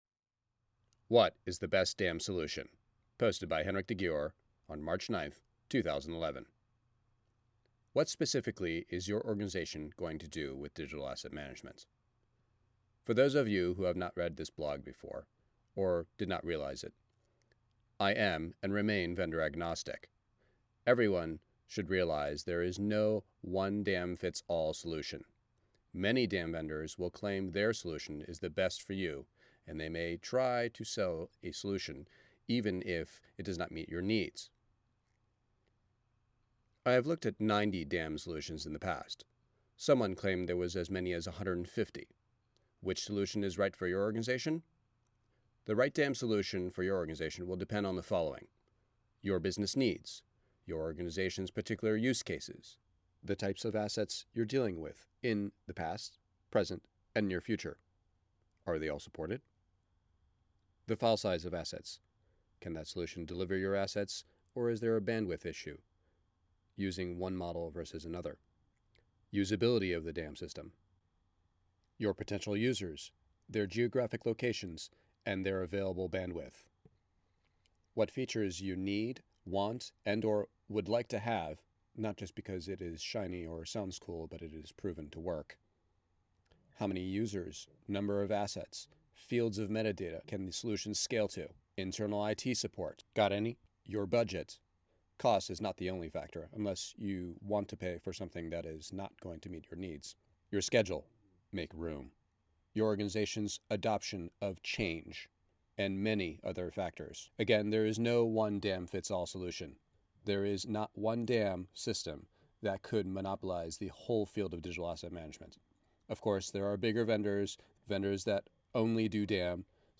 Written and read